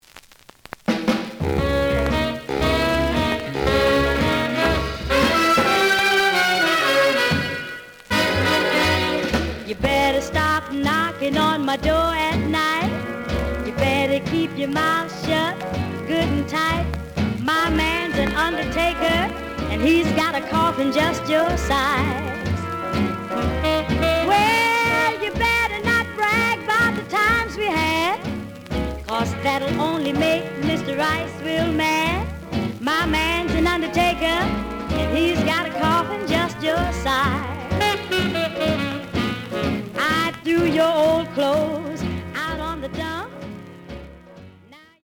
試聴は実際のレコードから録音しています。
●Format: 7 inch
●Genre: Rhythm And Blues / Rock 'n' Roll